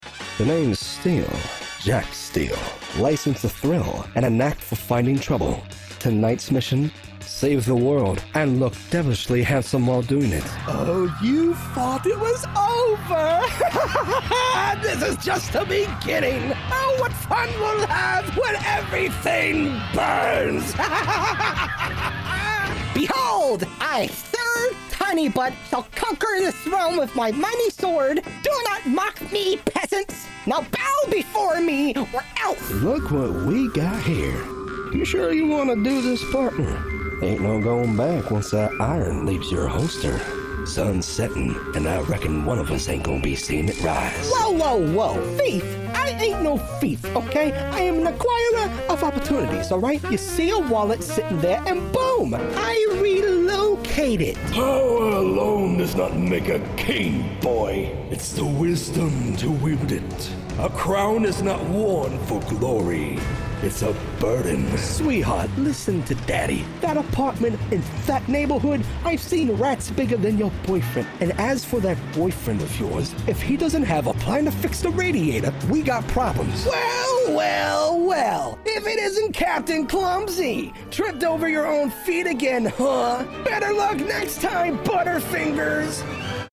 animation 🎬